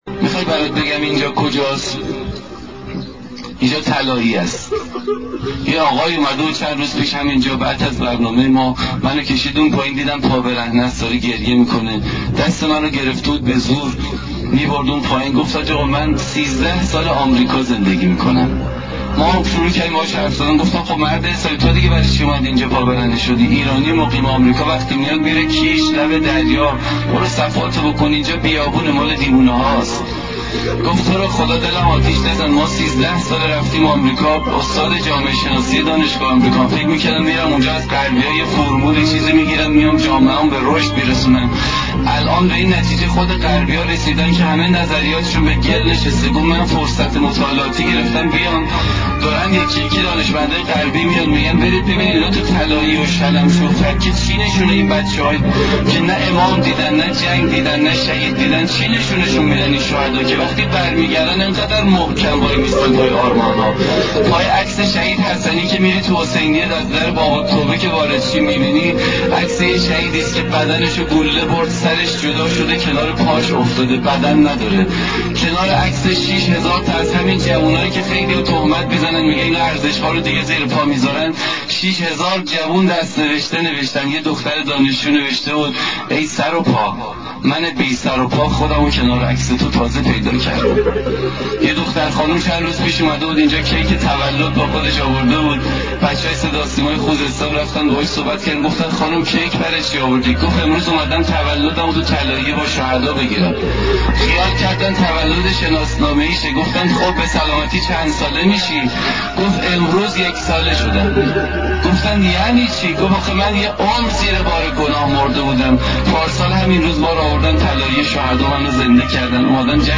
گلف چند رسانه‌ای صوت روایتگری خاک مقدس خاک مقدس مرورگر شما از Player پشتیبانی نمی‌کند.